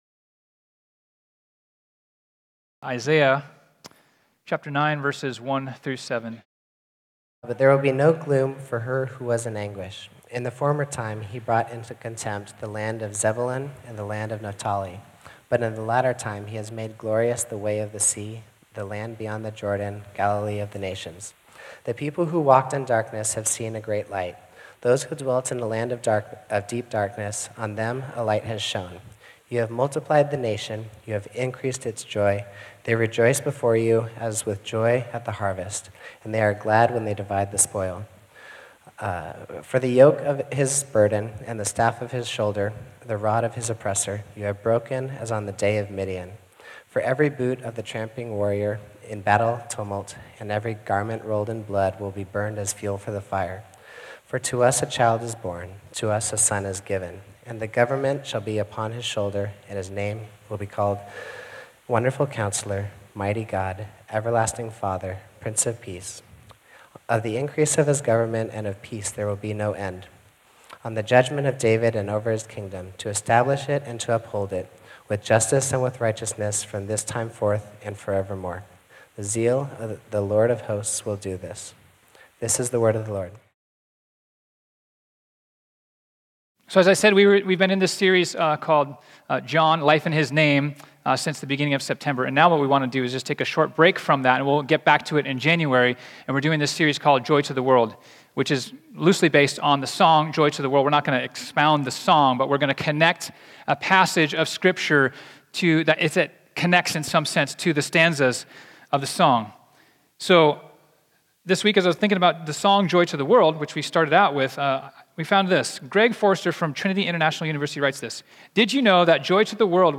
sermon
This sermon was originally preached on Sunday, December 8, 2019.